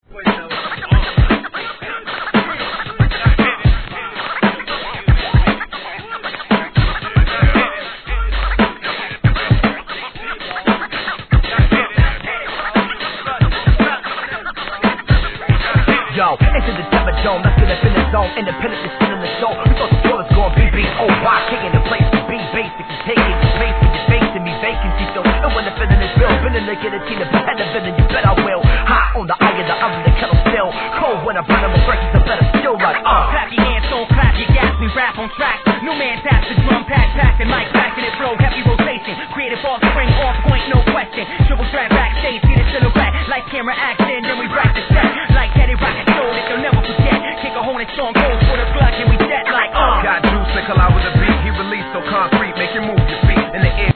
HIP HOP/R&B
駆け抜けるような疾走感溢れるトラックで息をつかぬようなRAP STYLEで畳み込む2006年作品!